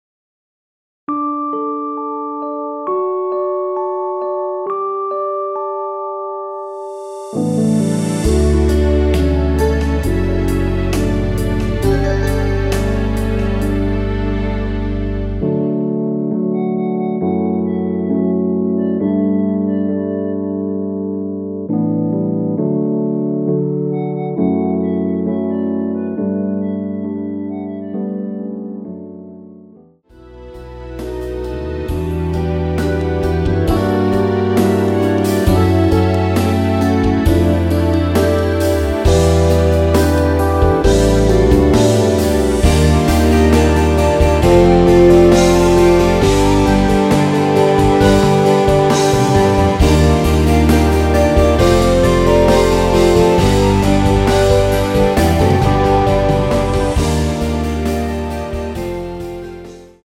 멜로디 MR입니다.
노래방에서 노래를 부르실때 노래 부분에 가이드 멜로디가 따라 나와서
앞부분30초, 뒷부분30초씩 편집해서 올려 드리고 있습니다.
중간에 음이 끈어지고 다시 나오는 이유는